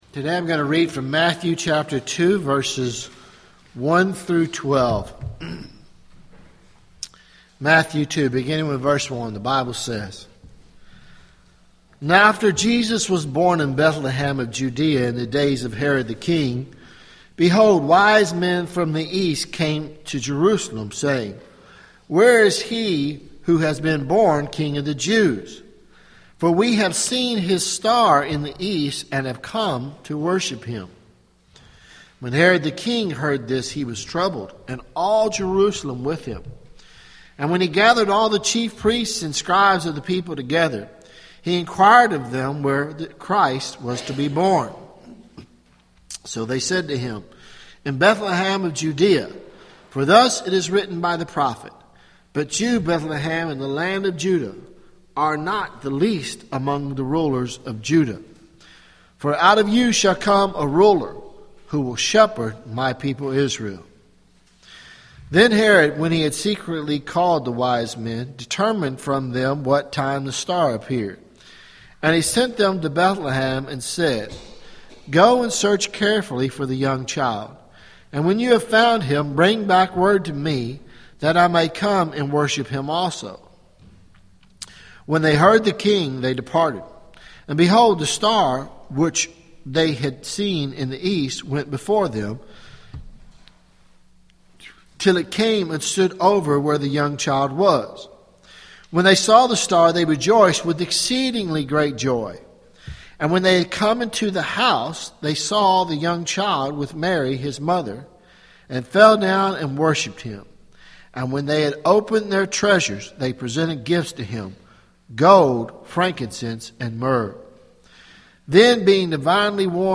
Sermons Jan 04 2015 “The Magi Visit Jesus